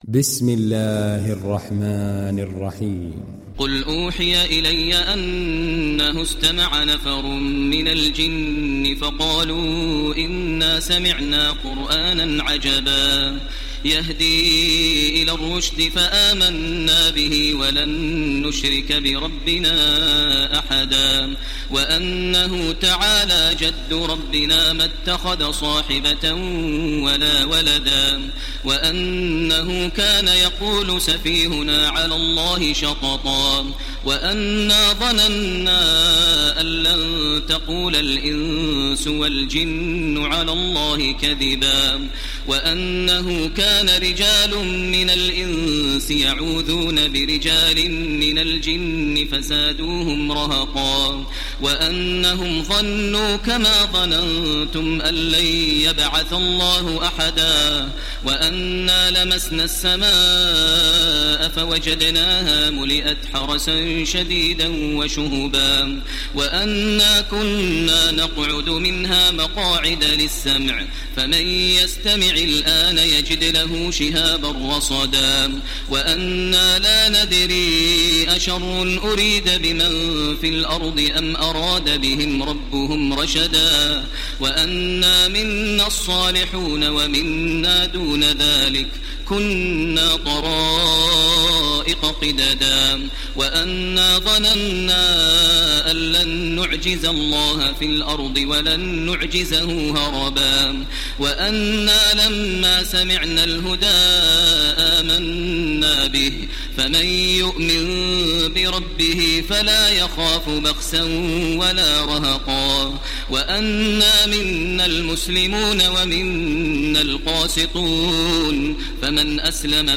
Download Surat Al Jinn Taraweeh Makkah 1430